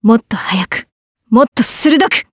Ripine-Vox_Skill4_jp.wav